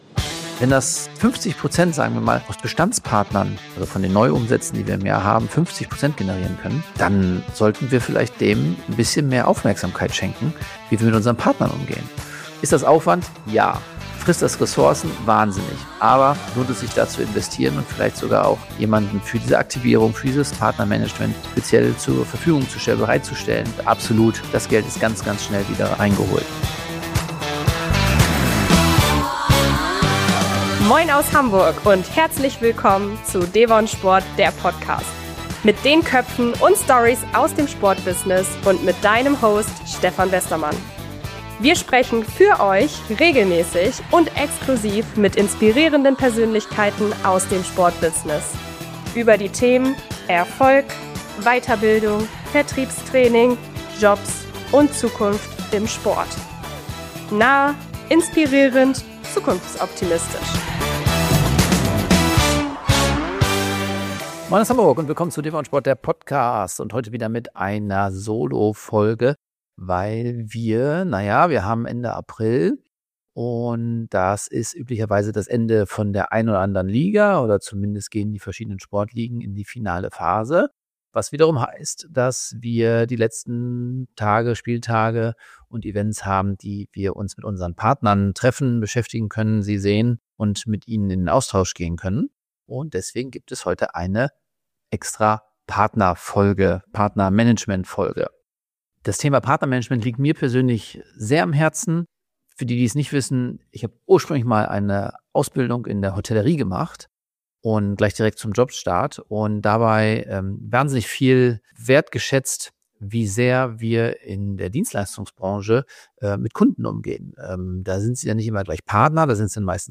In dieser Solo-Folge spreche ich darüber, warum professionelles Partnermanagement im Sportbusiness nicht erst bei der Vertragsverlängerung beginnt, sondern genau...